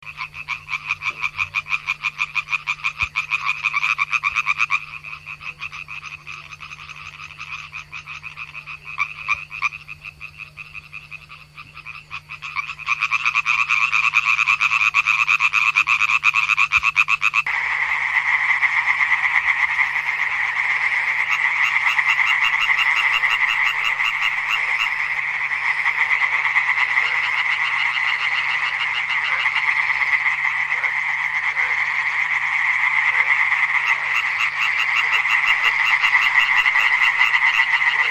Laubfrosch – Natur erleben – beobachten – verstehen
Hier hören Sie die Rufe eines einzelnen und einer ganzen Gruppe von Laubfröschen.
rufe_laubfrosch_froschnetz.ch_.mp3